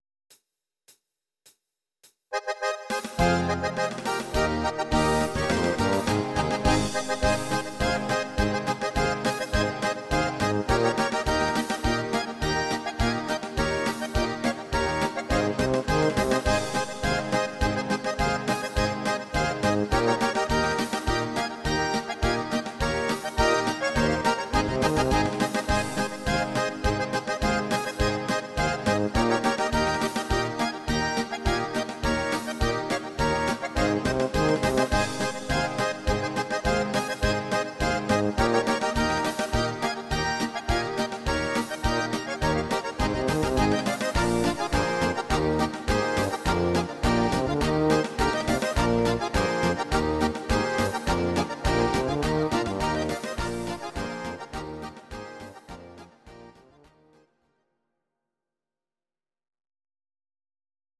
These are MP3 versions of our MIDI file catalogue.
Please note: no vocals and no karaoke included.
(instr. Akkordeon)